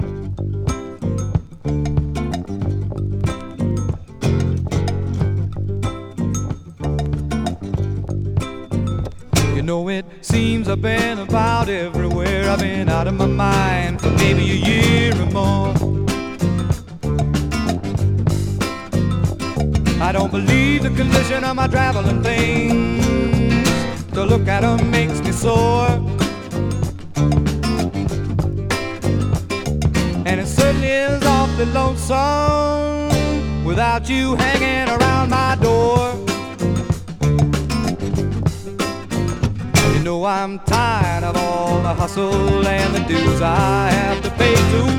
Folk, Rock, Pop, SSW　USA　12inchレコード　33rpm　Stereo